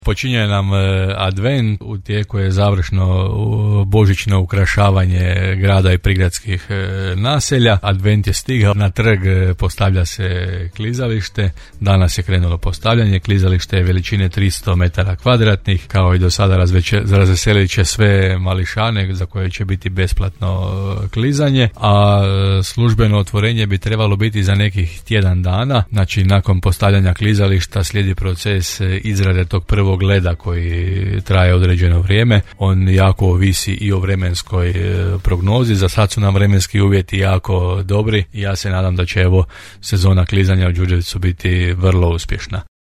– rekao je gradonačelnik Janči u emisiji Gradske teme: